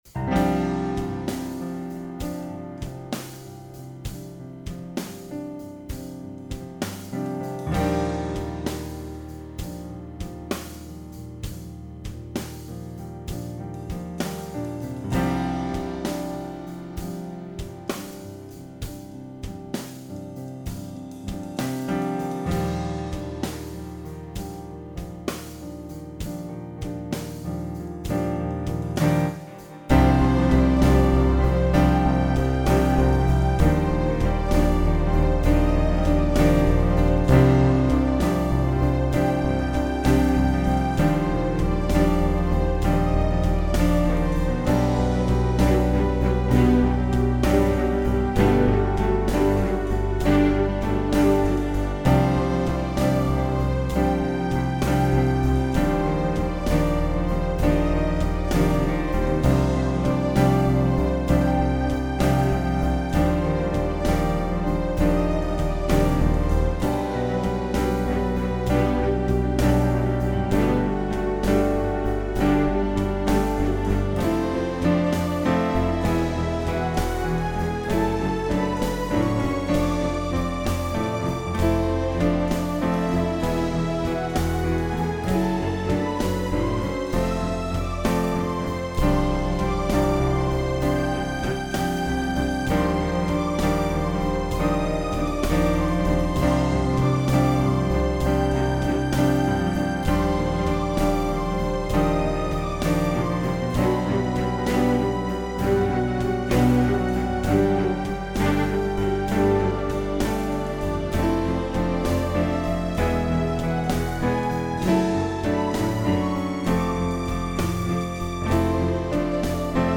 Really early instrumental kinda proggyish but not really.